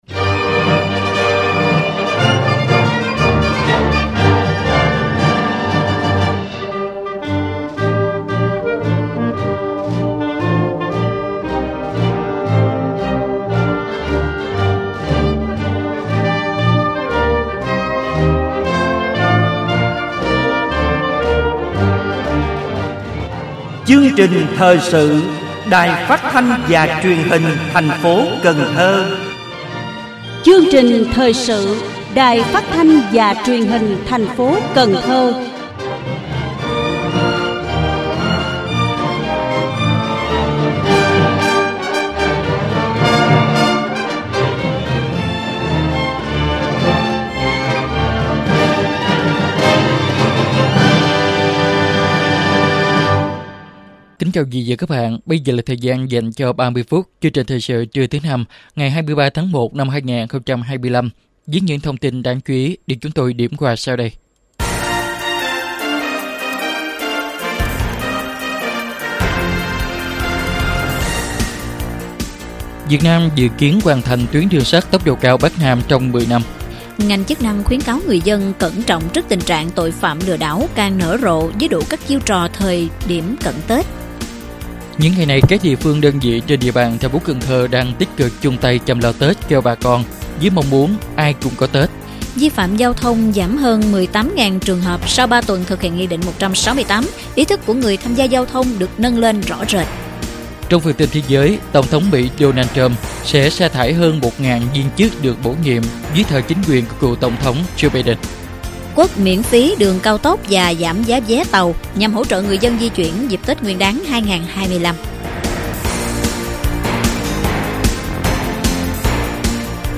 Thời sự phát thanh trưa 23/1/2025